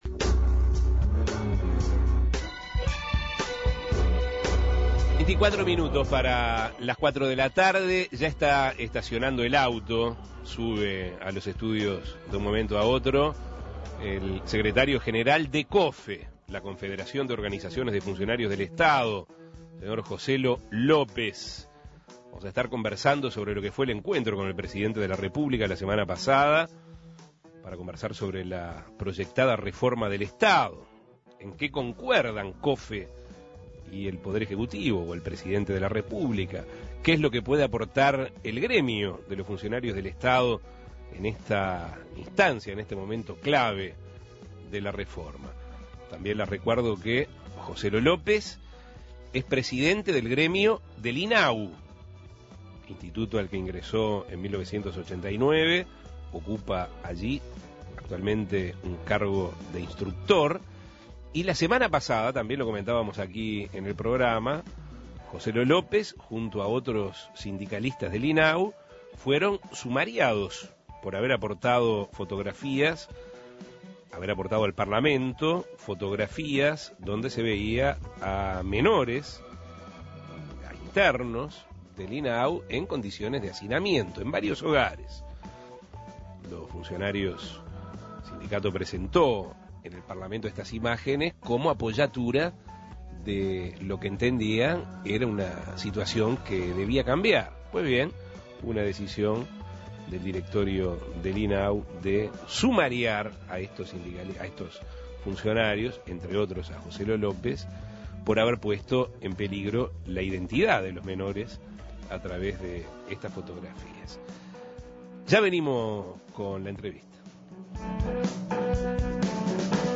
También hizo mención a los aportes que pueda dar el sindicato de funcionarios en esta instancia de la reforma. Escuche la entrevista.